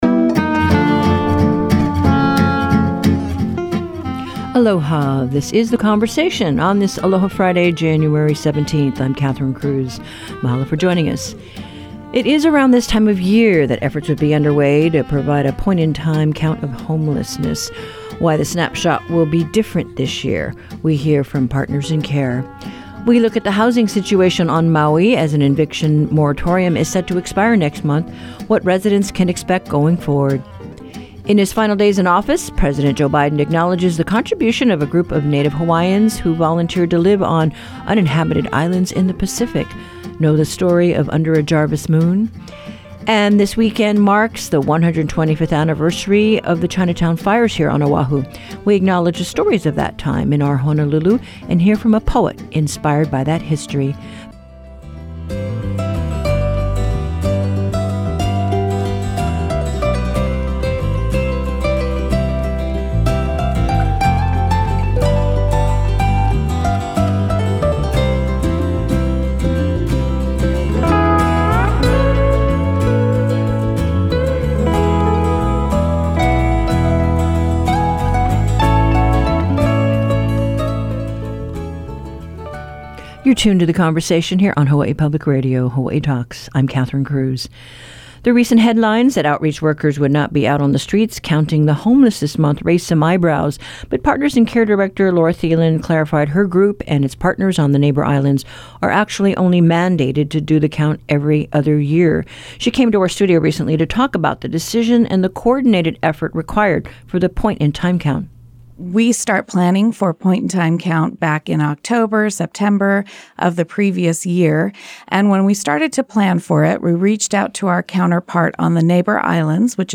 Whether you live in our state or far from our shores, you’ll know what’s happening in Hawaiʻi with HPR's daily hour of locally focused discussions of public affairs, ideas, culture and the arts. Guests from across the islands and around the world provide perspectives on life in Hawaiʻi — and issues that have not yet reached Hawaiʻi.